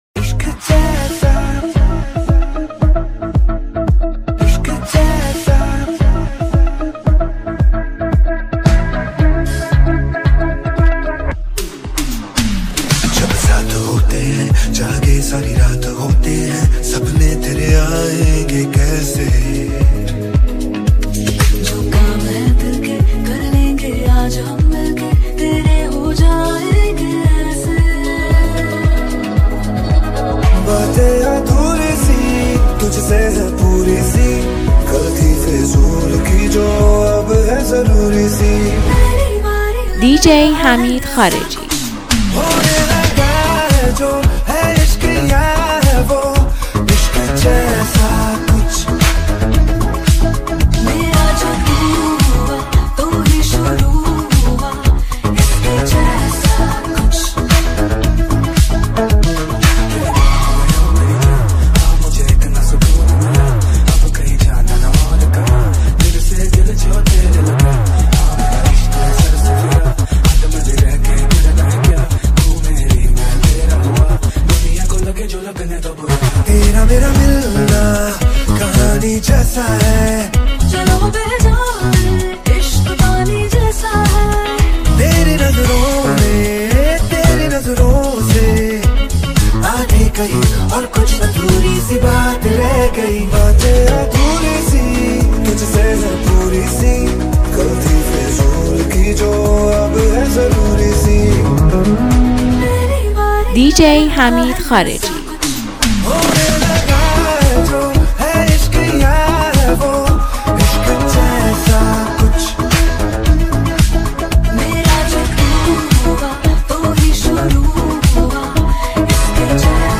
ریمیکس هندی